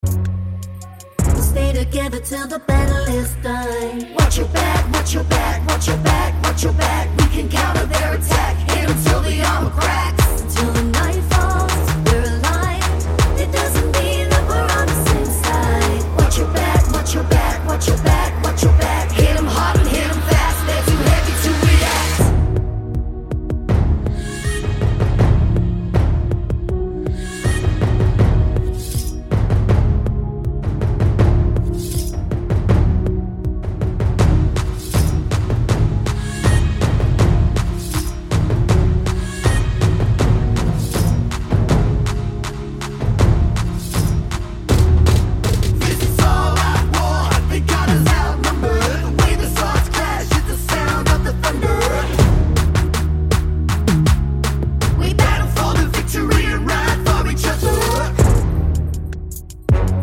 no Backing Vocals Soundtracks 3:08 Buy £1.50